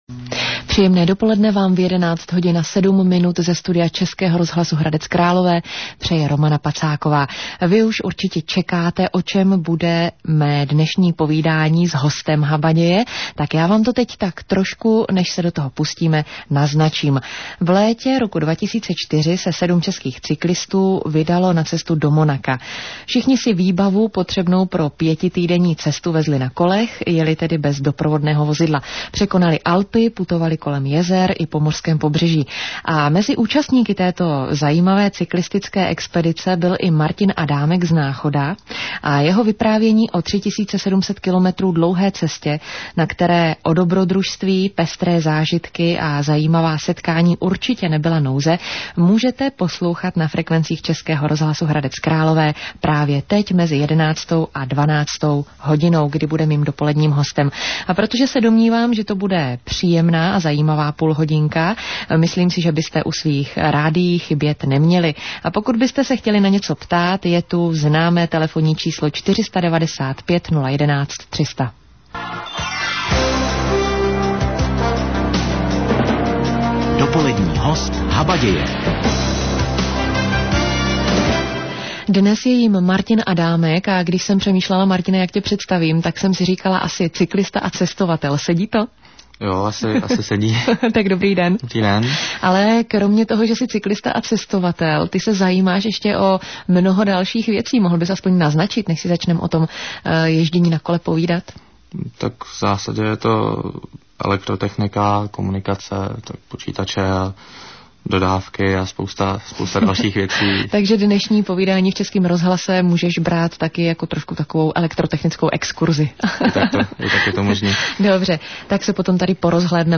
Rozhovor v �esk�m rozhlasu Hradec Kr�lov�